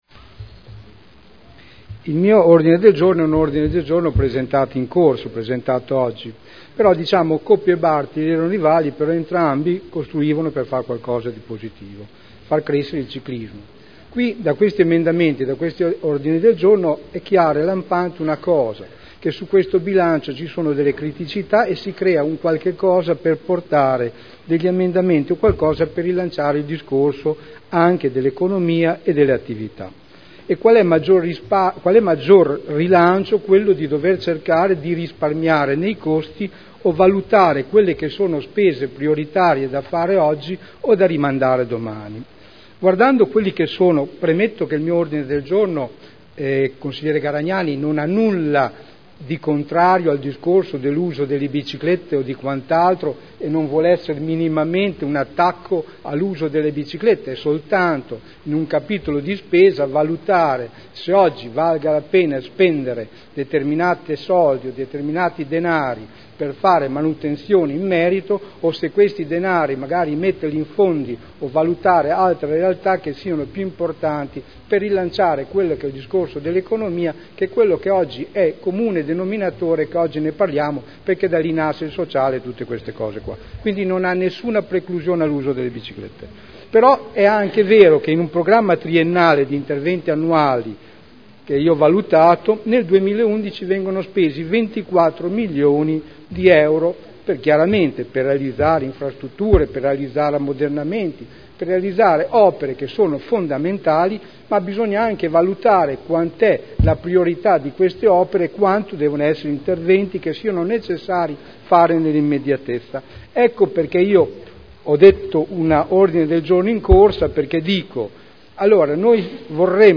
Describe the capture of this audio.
Seduta 28/03/2011. Presentazione odg n. 36421. Piste ciclabili.